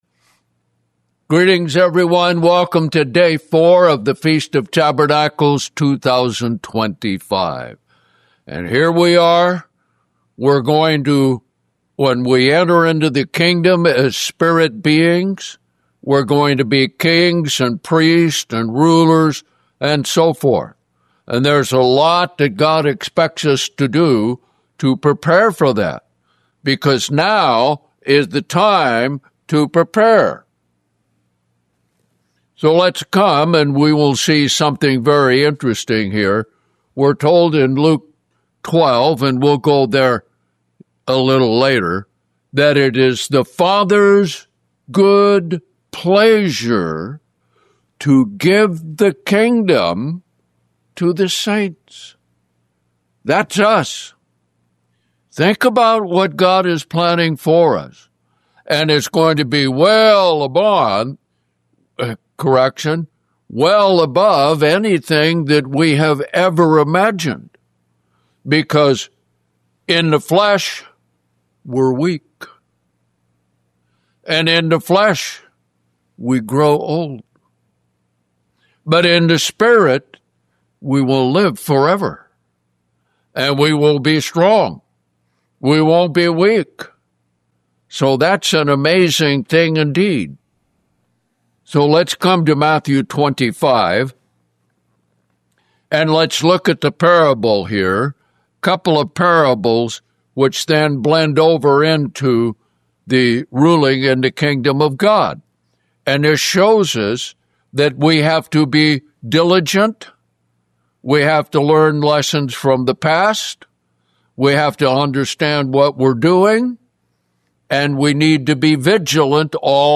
(FOT Day 4)